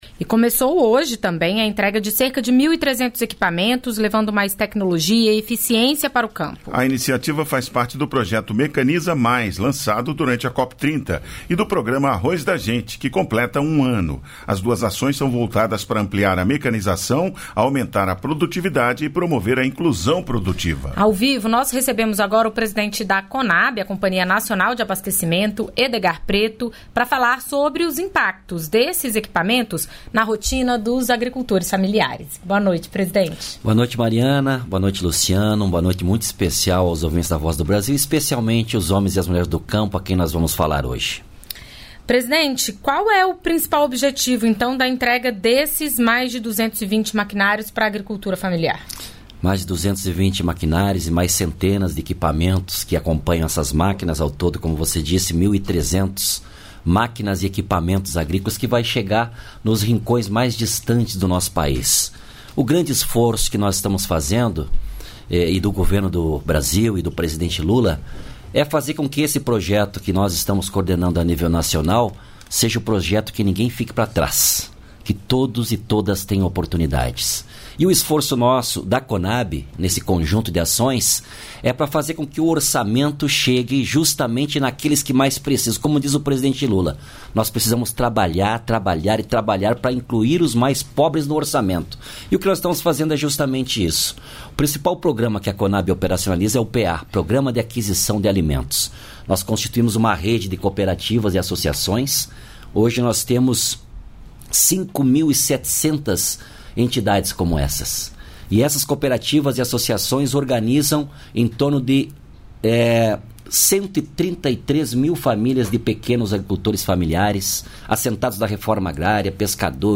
O ministro fala sobre o Desenrola Rural, uma oportunidade para que agricultores familiares quitem dívidas e limpem o nome. Além disso, os agricultores familiares atingidos pelo rompimento da barragem do Fundão, em Mariana (MG), em 2015, vão ser atendidos pelo Programa de Transferência Renda Rural.
Entrevistas da Voz Ministro do Desenvolvimento Agrário e Agricultura Familiar, Paulo Teixeira O ministro fala sobre o Desenrola Rural, uma oportunidade para que agricultores familiares quitem dívidas e limpem o nome.